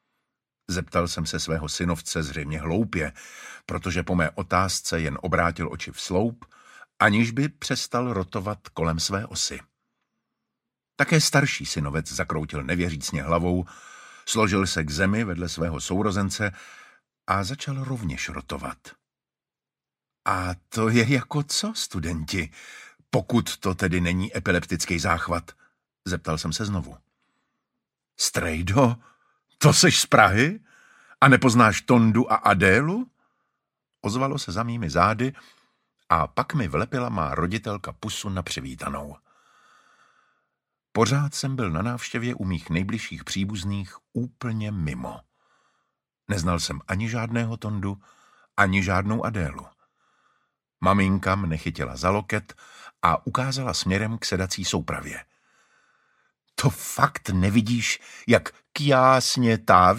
Audiobook
MP3 Audiobook - author Jan Zlatohlávek, read by David Matásek, in Czech,
Read: David Matásek